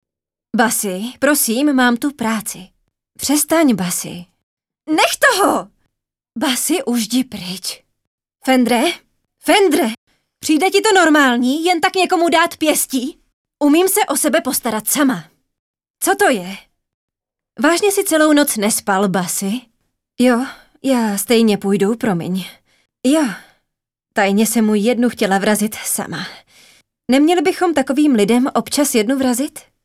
ukázka dabing:
ukazka-dabing.mp3